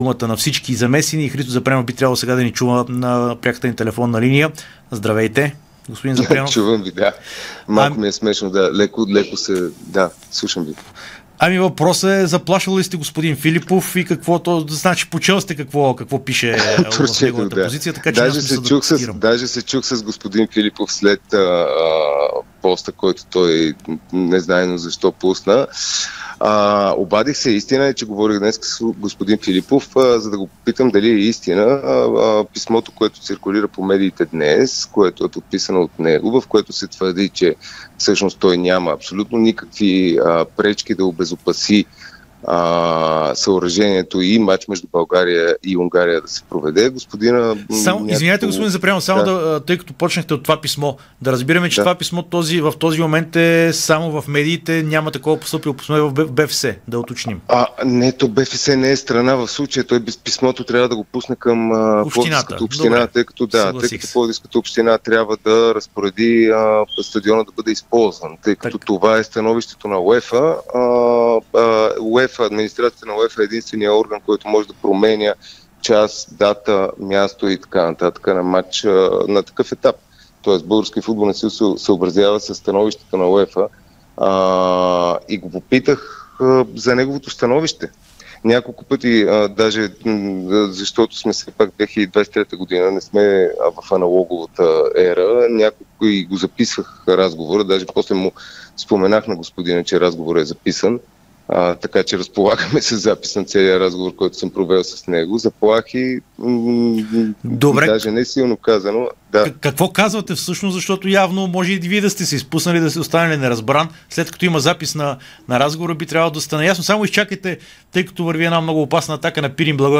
говори специално пред Дарик радио